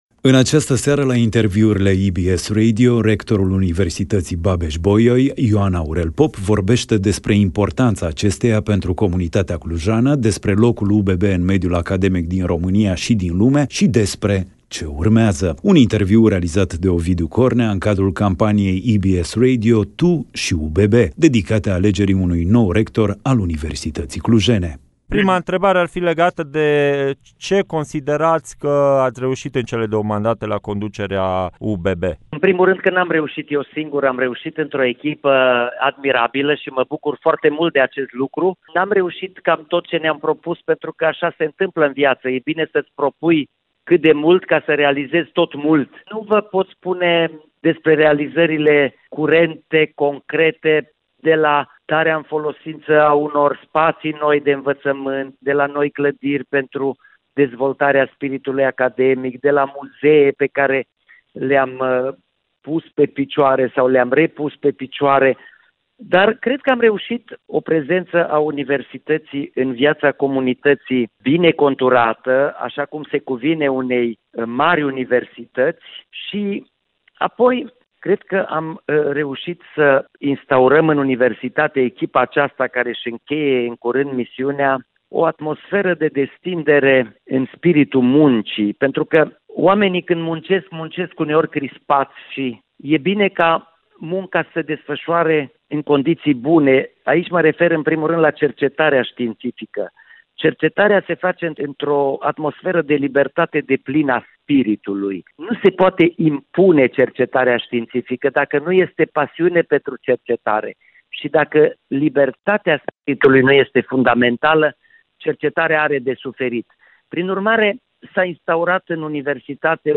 Interviu-Ioan-Aurel-Pop.mp3